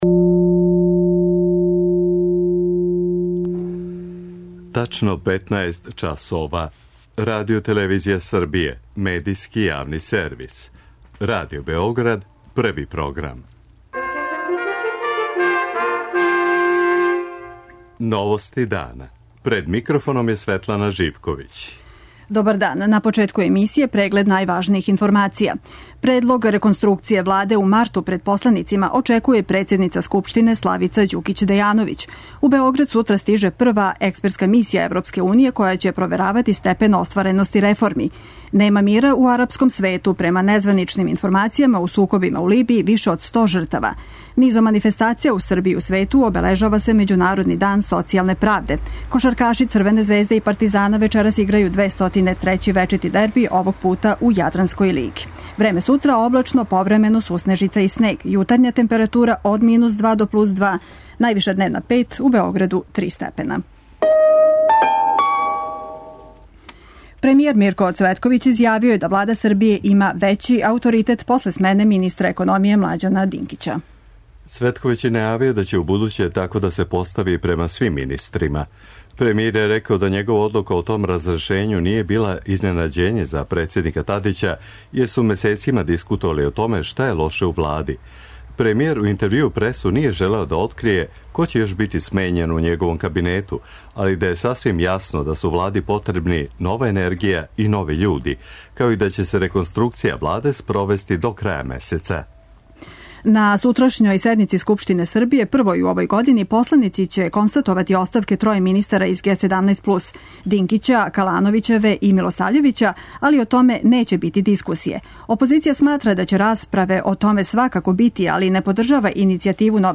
Тим поводом, гост Новости дана је Државни секретар у Министарству правде Слободан Хомен.
преузми : 14.87 MB Новости дана Autor: Радио Београд 1 “Новости дана”, централна информативна емисија Првог програма Радио Београда емитује се од јесени 1958. године.